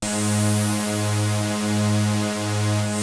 KORG G#3 3.wav